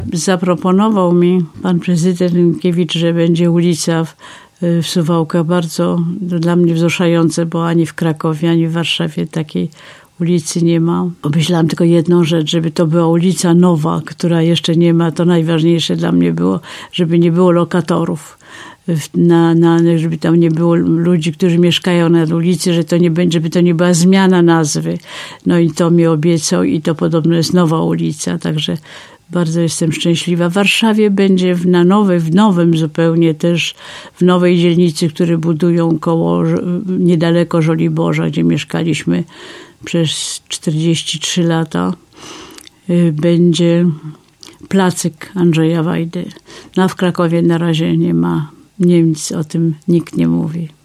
Później gościła w studiu Radia 5.